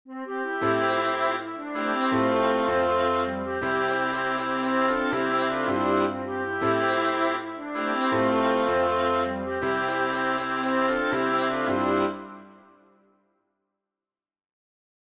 A cappella